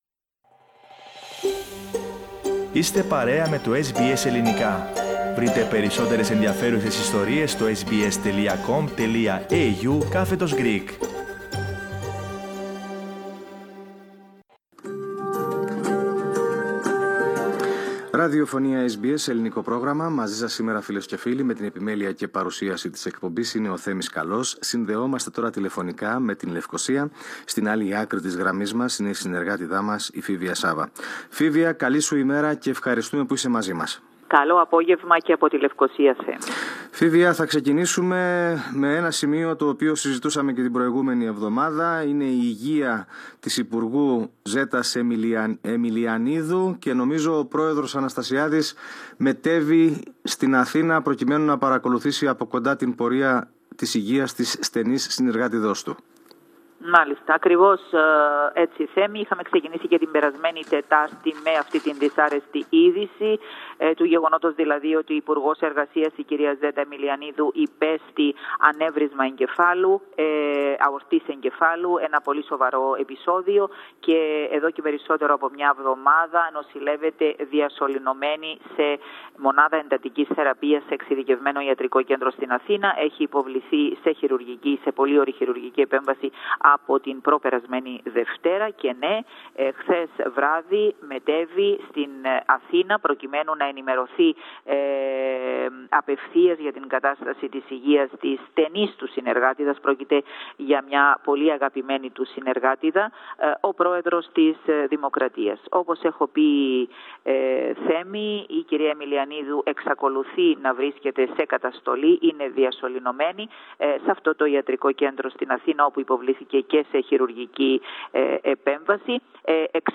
εβδομαδιαίας ανταπόκρισης από την Κύπρο